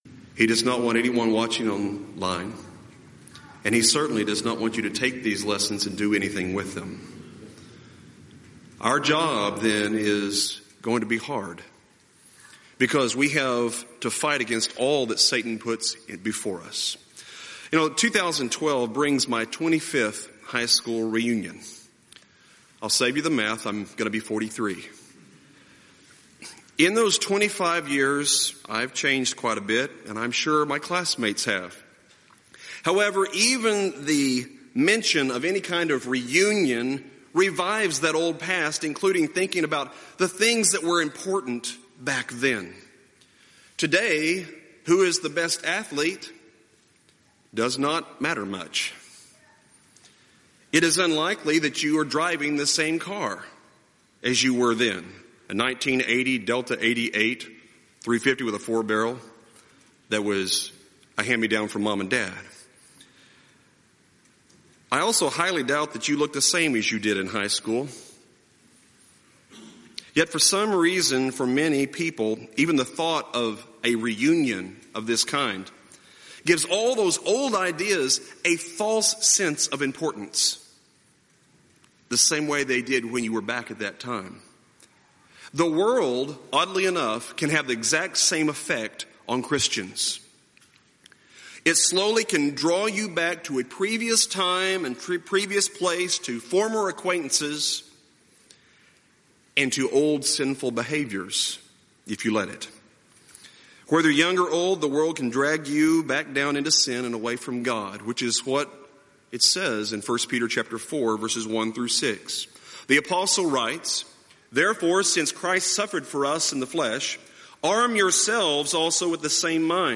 Event: 31st Annual Southwest Lectures
lecture